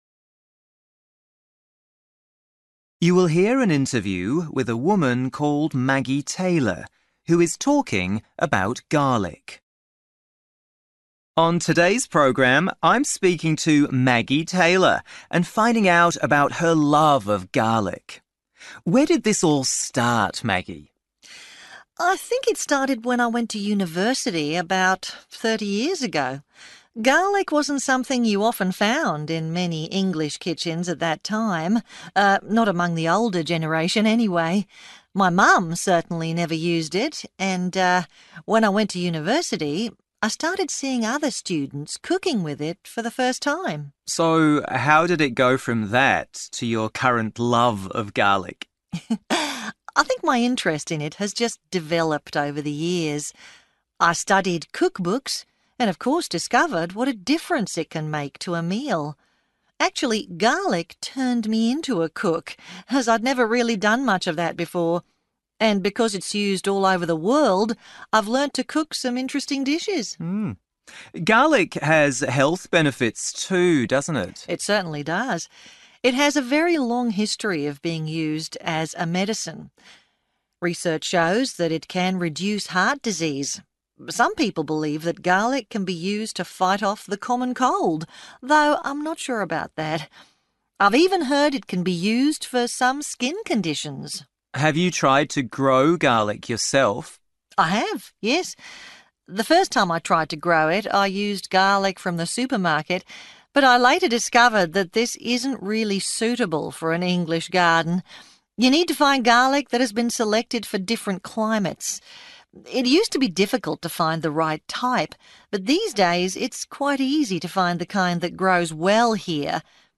Listening: garlic